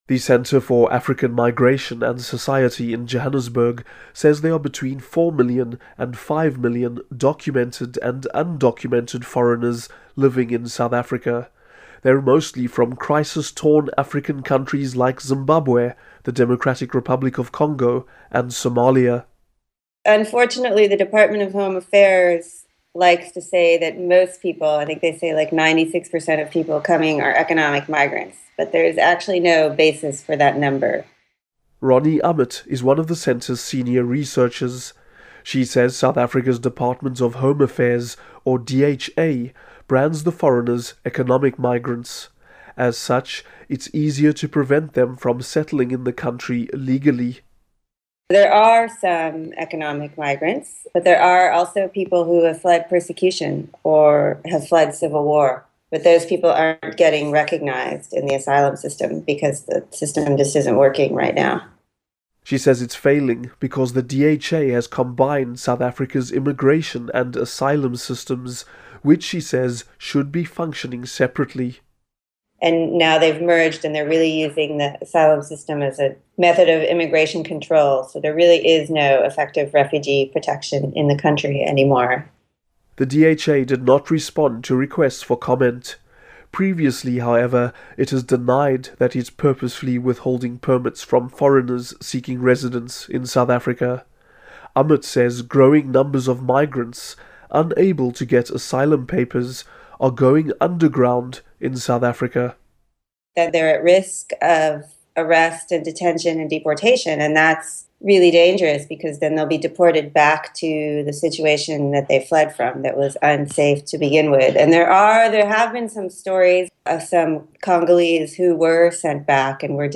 Listen to report on migrants in South Africa